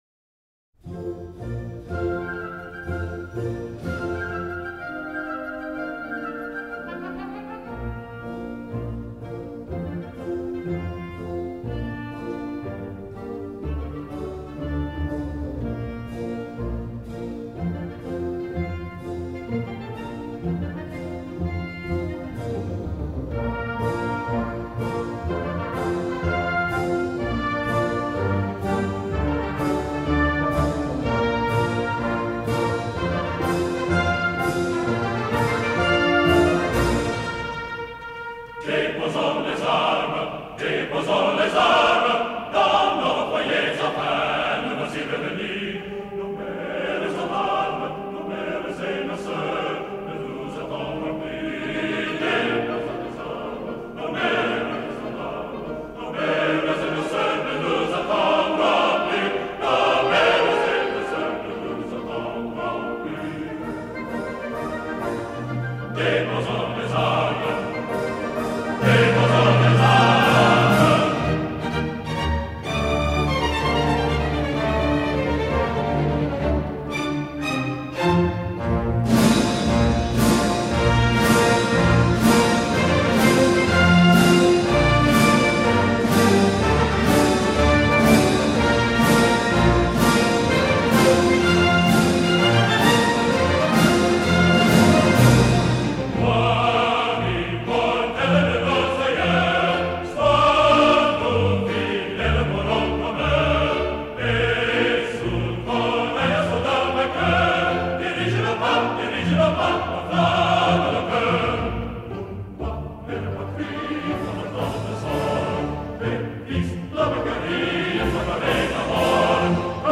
Un extrait d’opéra par jour !
Cette fois-ci, ils y a de nombreux chanteurs ou choristes.